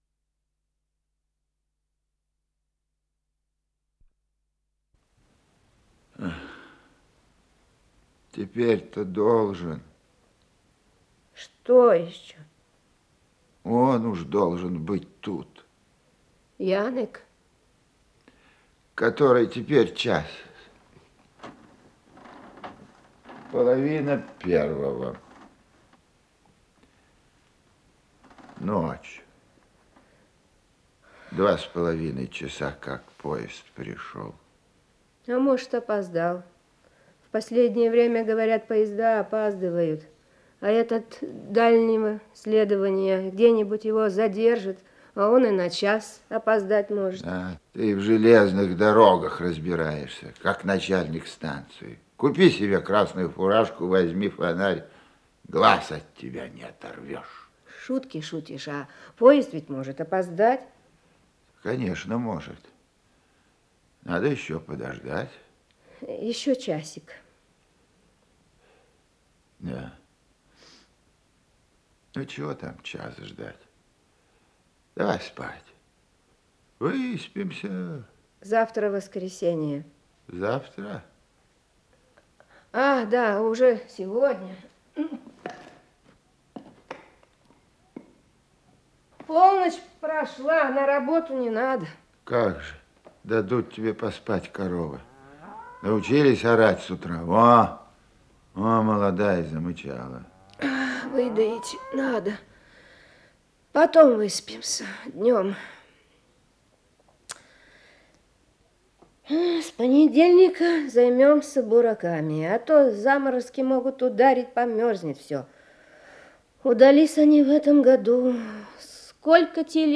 Исполнитель: Римма Быкова, Леонид Марков - чтение
Радиопостановка